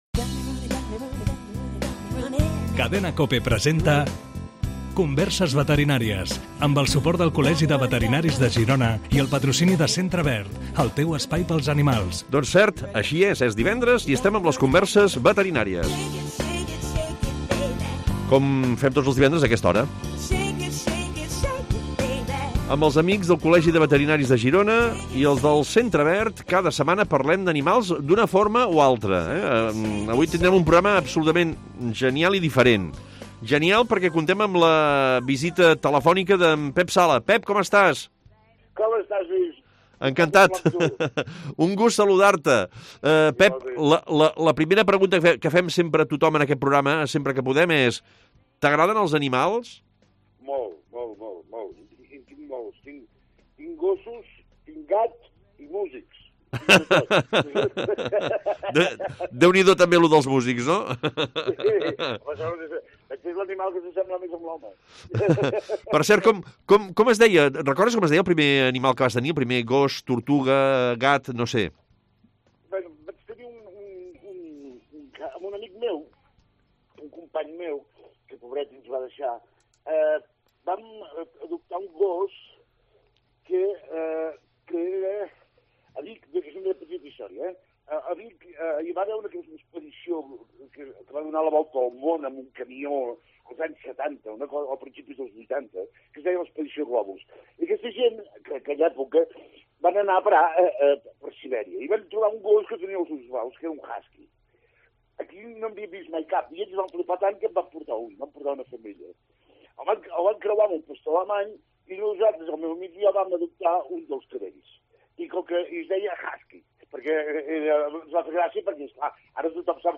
AUDIO: Conversem amb el cantant Pep Sala sobre música, animals i gastronomia